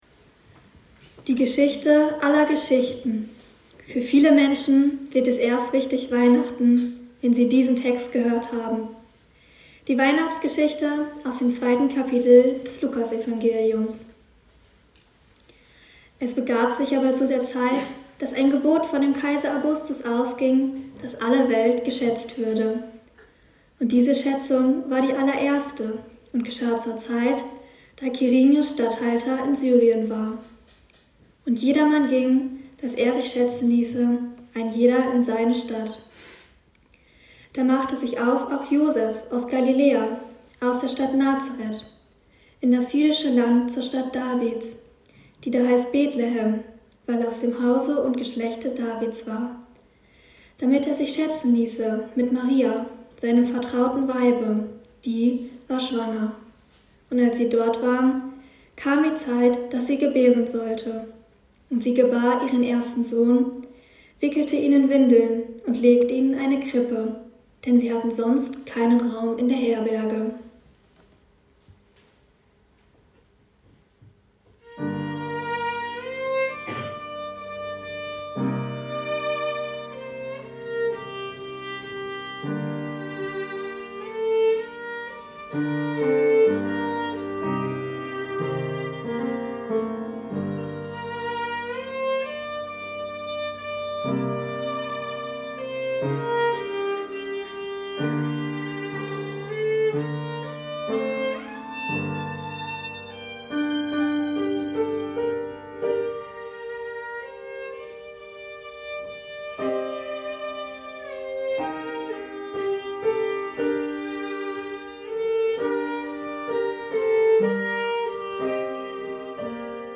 Andachten und Gottesdienste aus der Evangelisch-lutherischen Andreasgemeinde Wallenhorst, zum Nachlesen und Nachhören